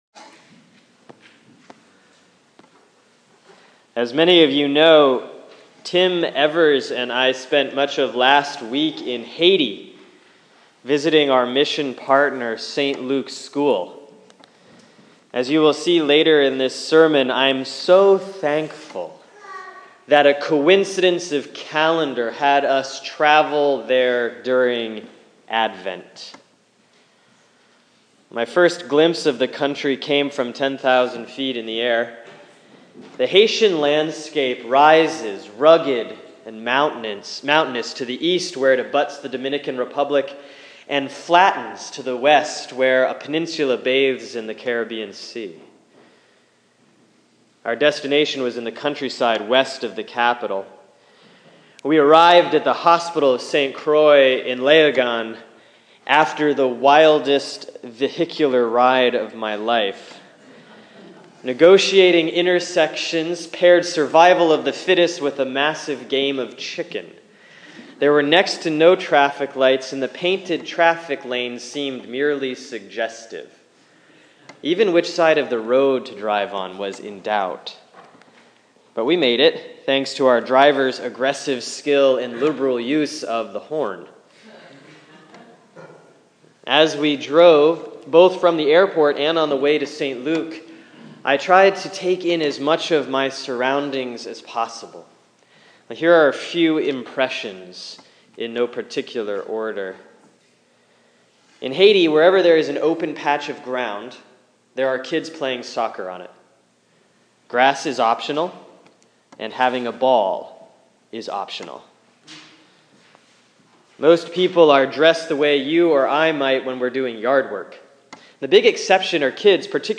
Sermon for Sunday, December 6, 2015 || Advent 2C